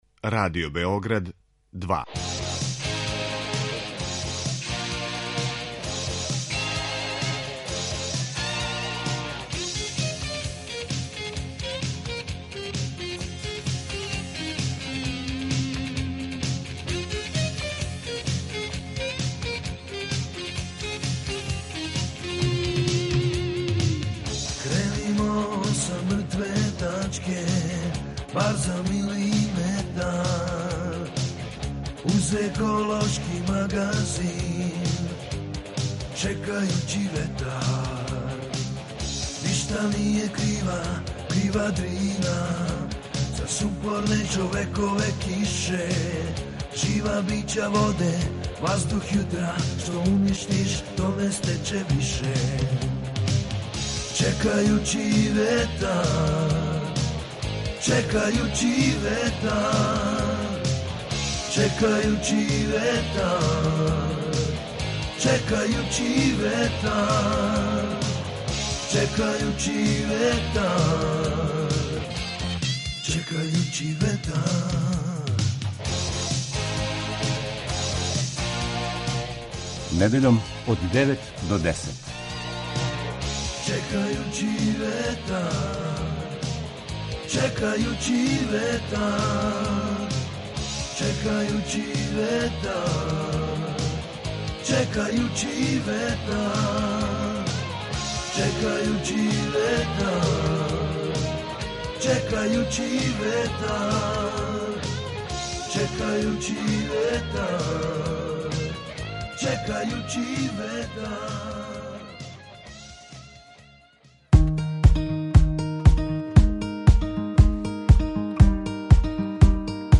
PRESLUŠAJ: Čekajući vetar - ekološki magazin Radio Beograda 2 koji se bavi odnosom čoveka i životne sredine, čoveka i prirode.